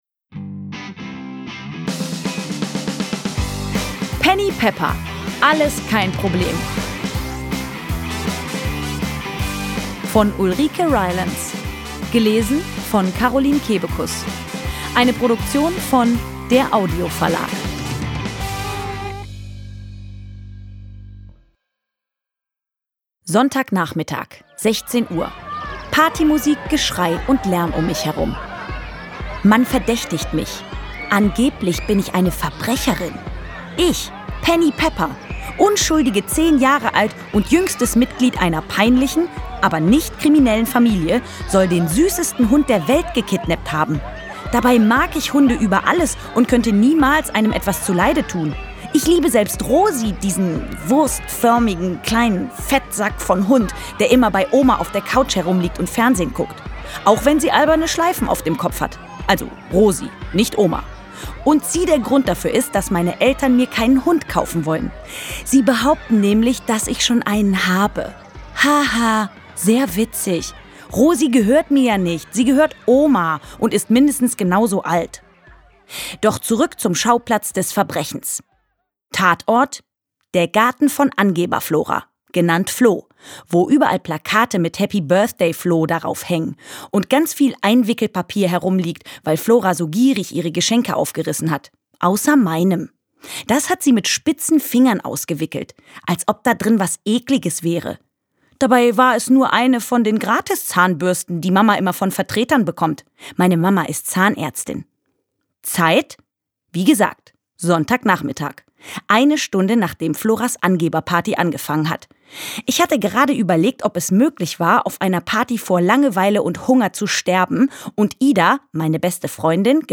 Szenische Lesungen mit Musik mit Carolin Kebekus (2 CDs)
Carolin Kebekus (Sprecher)